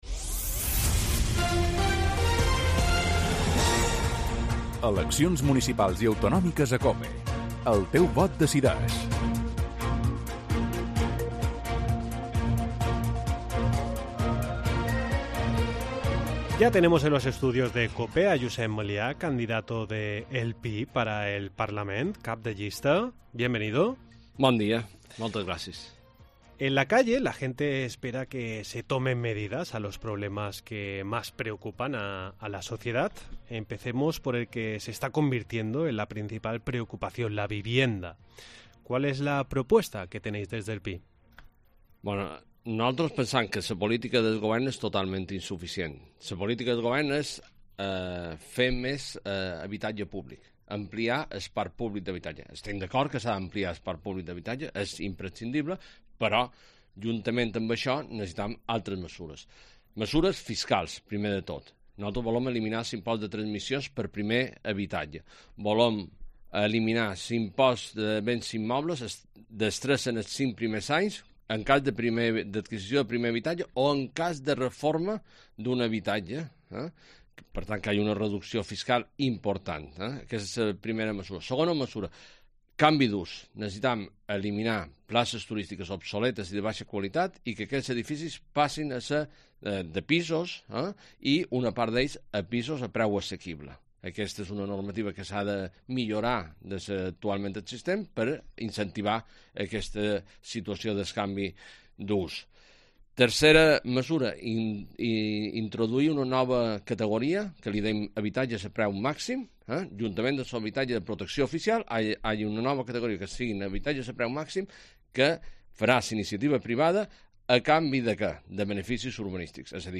AUDIO: El próximo 28 de mayo se votarán las elecciones autonómicas. Por este motivo hoy comenzamos una ronda de entrevistas con los diferentes...